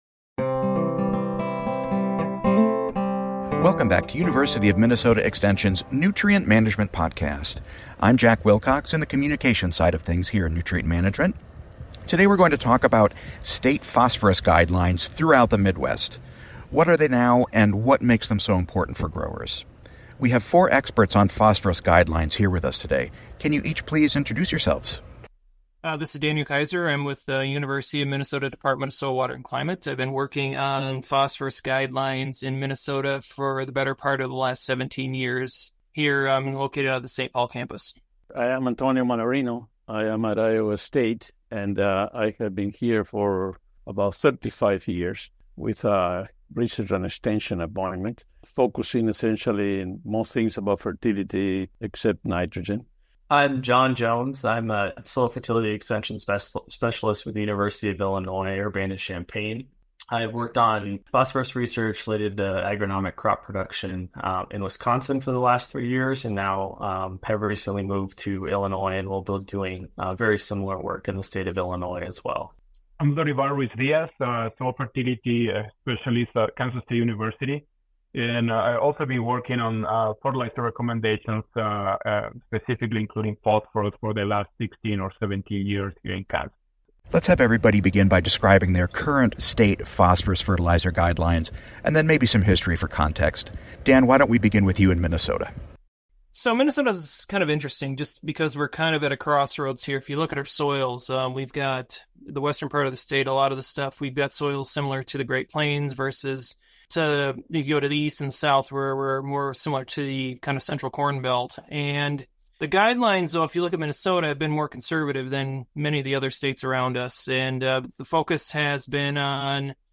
Phosphorus fertilizer guidelines: Four Midwest experts talk profit, yield, soil tests & more